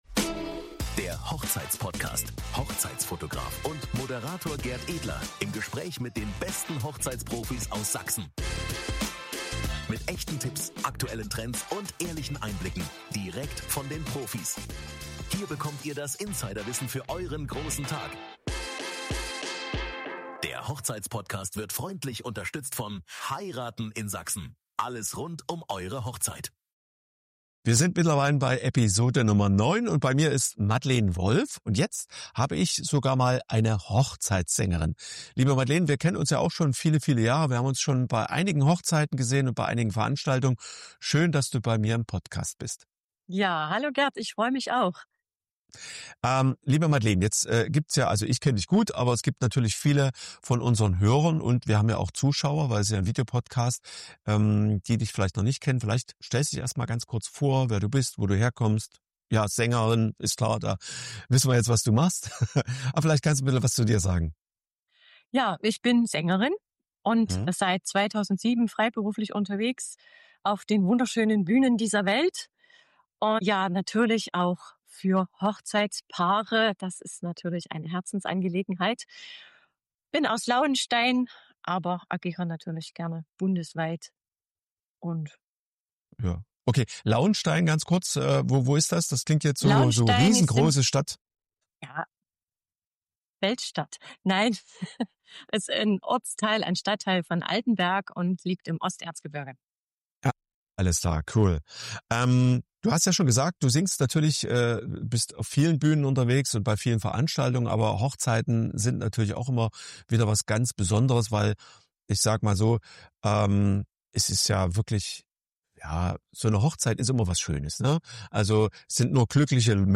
In dieser Episode von Der Hochzeitspodcast spreche ich mit der Hochzeitssängerin